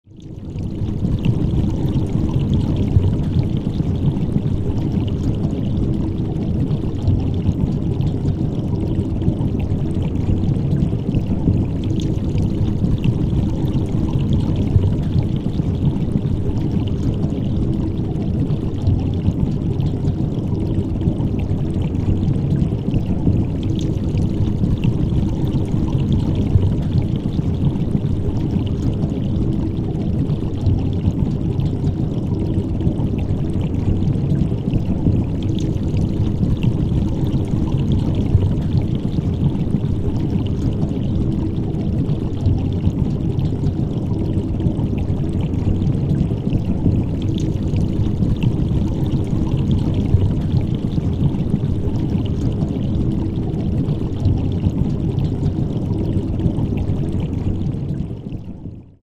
Подводная лодка движется, вода пузырится